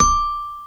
SFX / Xylophone C Major